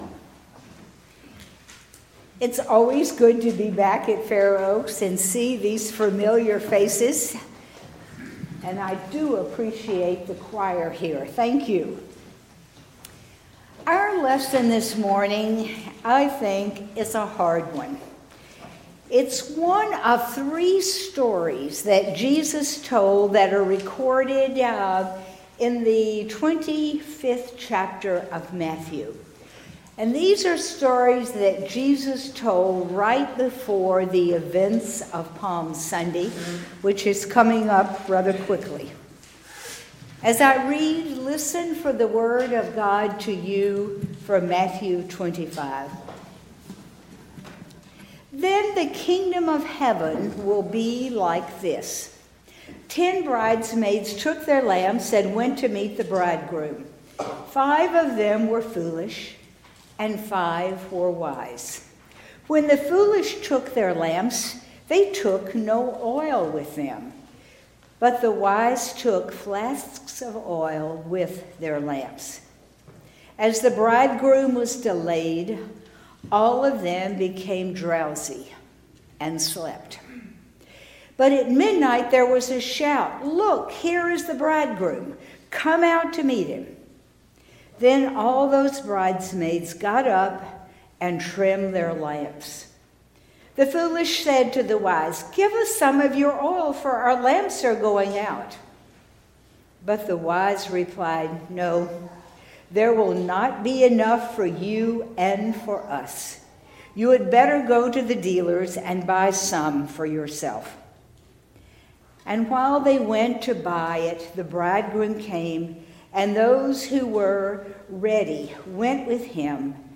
Gospel Lesson Matthew 25:1-13